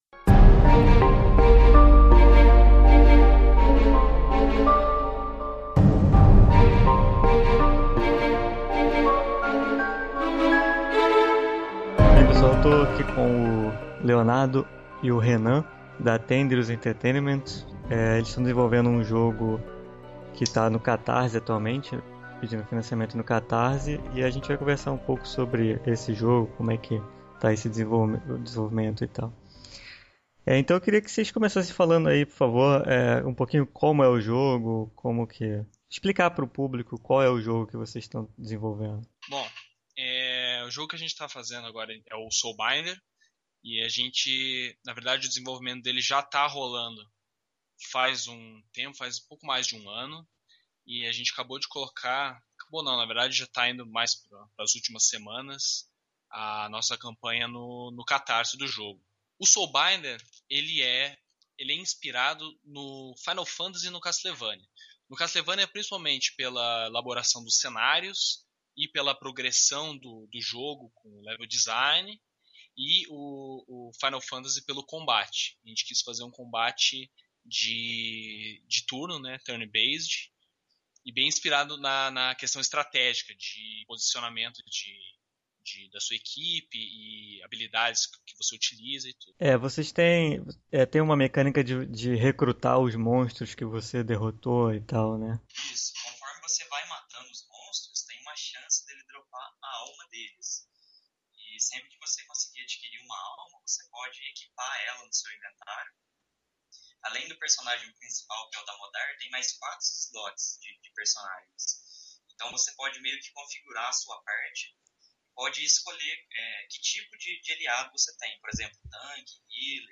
SelectCast Especial #02 – Entrevista